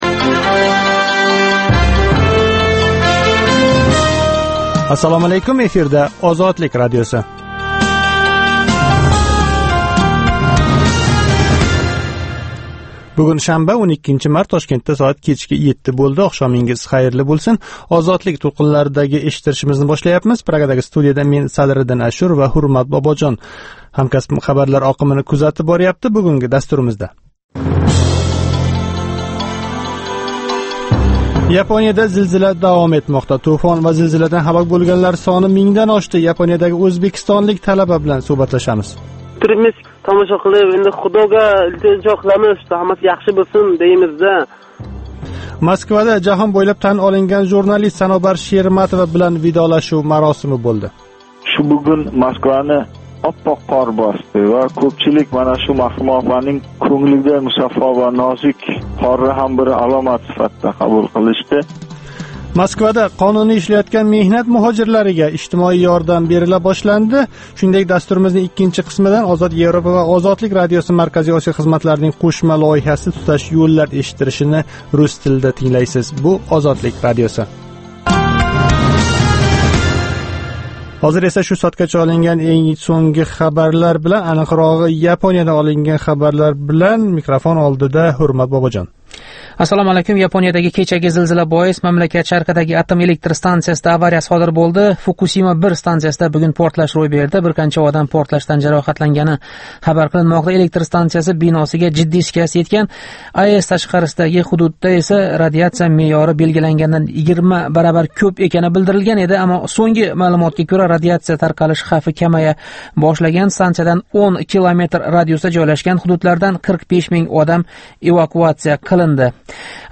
Озодлик радиосининг ўзбек тилидаги кечки жонли дастурида куннинг энг муҳим воқеаларига оид сўнгги янгиликлар¸ Ўзбекистон ва ўзбекистонликлар ҳаëтига доир лавҳалар¸ Марказий Осиë ва халқаро майдонда кечаëтган долзарб жараëнларга доир тафсилот ва таҳлиллар билан таниша оласиз